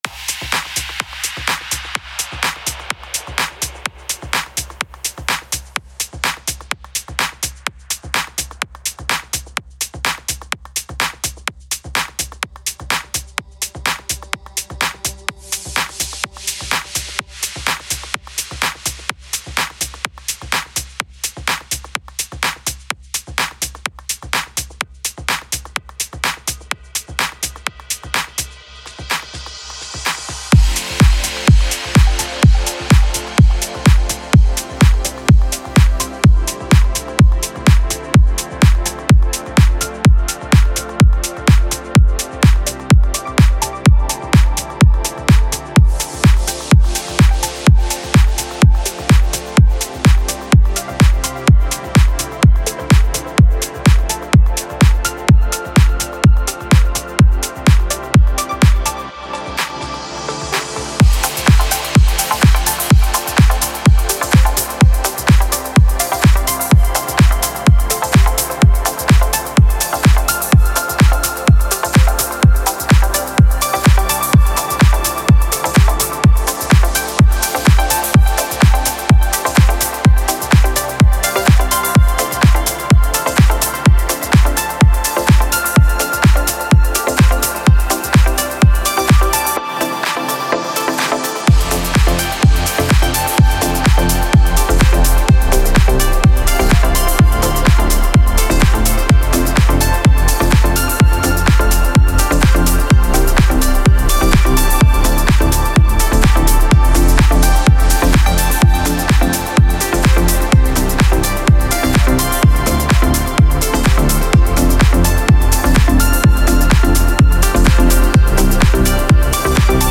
پر‌انرژی موسیقی بی کلام هاوس ورزشی